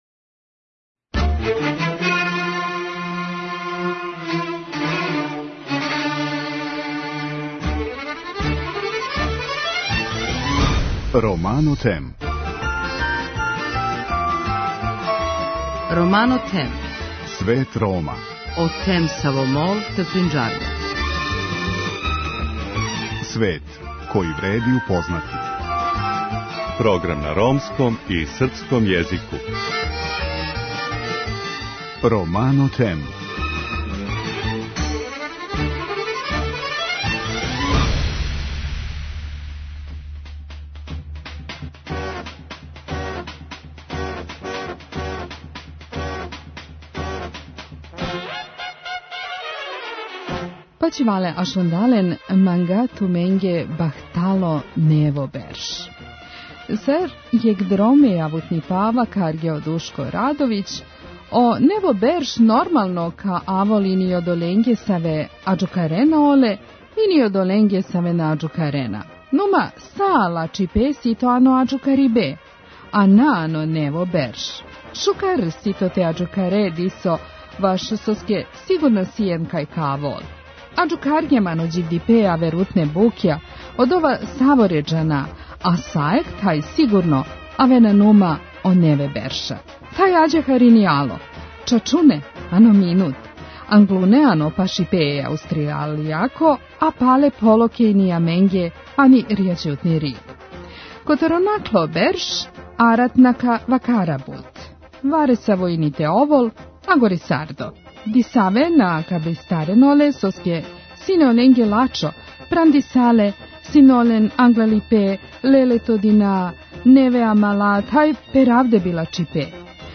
И, као што и приличи празничној емисији, и ове вечери ћемо, уз добру музику, слушати шта су нам пожелели наши највернији гости.
преузми : 7.14 MB Romano Them Autor: Ромска редакција Емисија свакодневно доноси најважније вести из земље и света на ромском и српском језику.